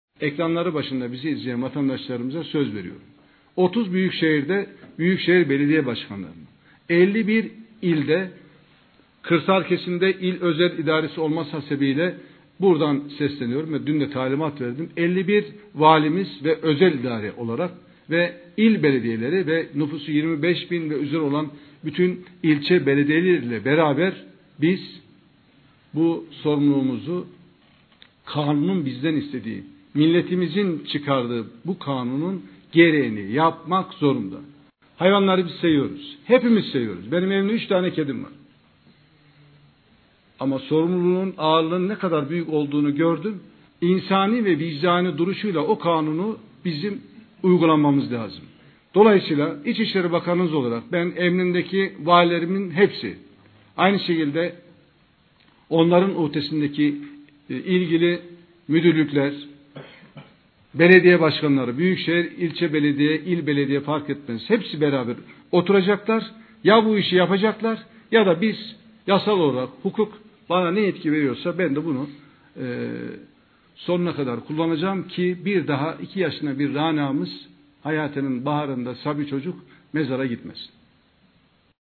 Bakan Ali Yerlikaya'nın "SÖZ VERİYORUM" dediği açıklaması - TIKLA DİNLE
ic-isleri-bakani-ali-yerlikaya-sokak-kopekleri-aciklamasi-9-mart-2025.mp3